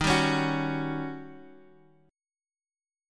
Eb7#9 chord